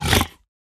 Minecraft Version Minecraft Version 1.21.5 Latest Release | Latest Snapshot 1.21.5 / assets / minecraft / sounds / mob / piglin_brute / hurt2.ogg Compare With Compare With Latest Release | Latest Snapshot
hurt2.ogg